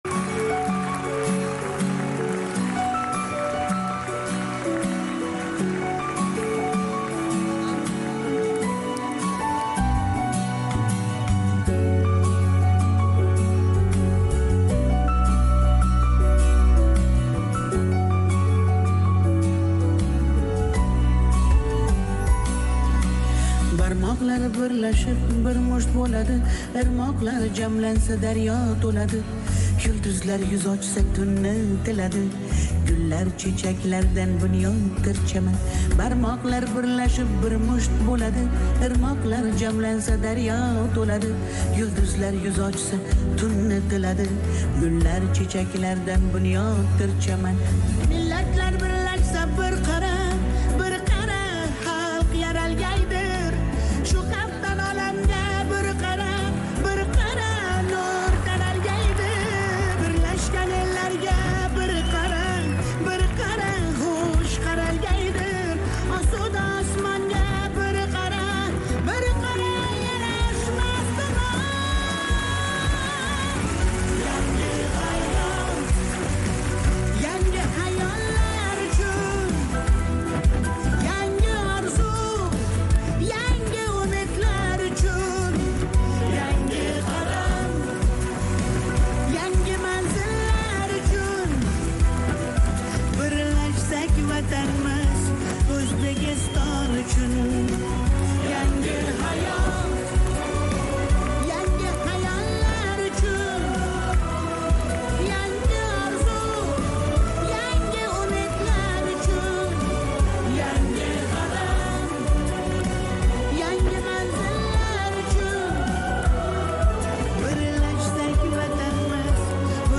Mustaqillikning 33 yilligiga bag'ishlangan konsertdan